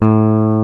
FLYING V 5.wav